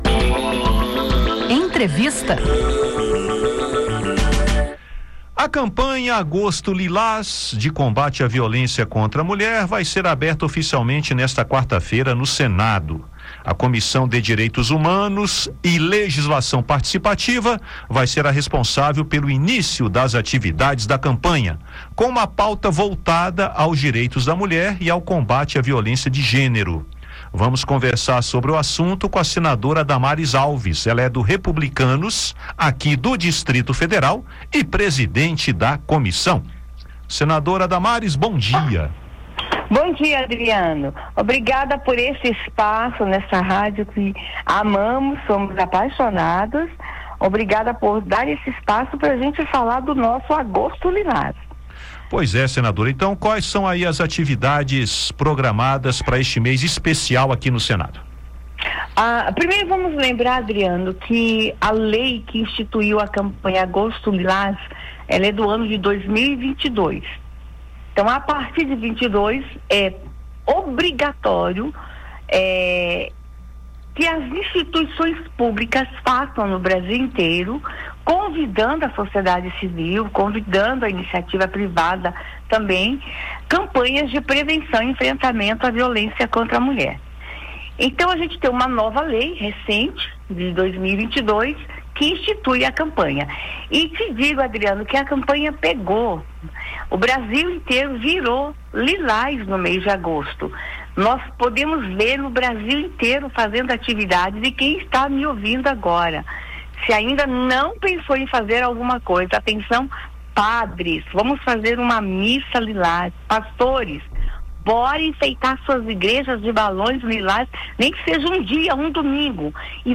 Ouça a entrevista com a senadora Damares Alves (Republicanos-DF), presidente da Comissão de Direitos Humanos e Legislação Participativa (CDH). Ela destaca a importância da iniciativa e as ações do Senado no âmbito dessa campanha.